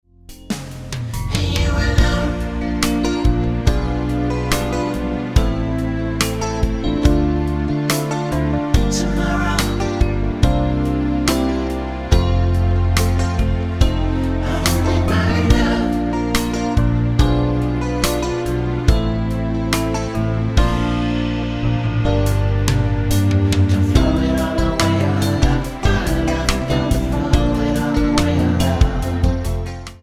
Tonart:F-G mit Chor